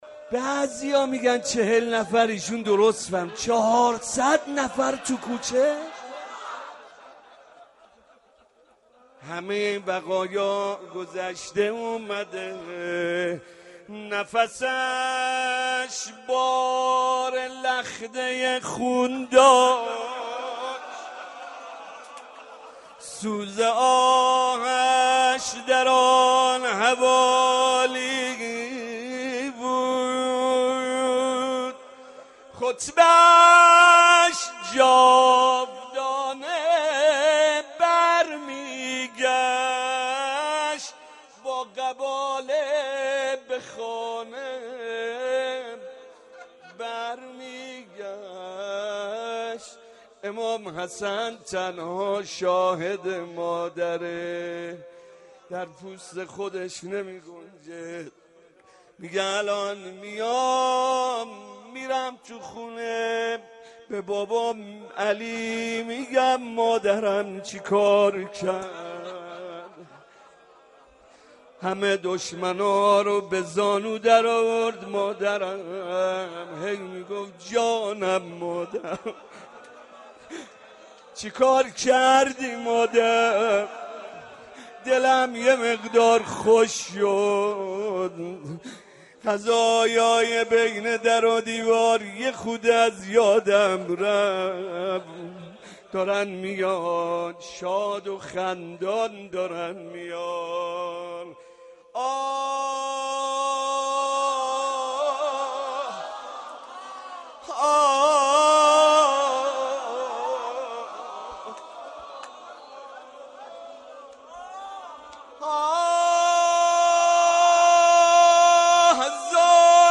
روضه خوانی در شهادت امّ المصایب «حضرت زهرا(س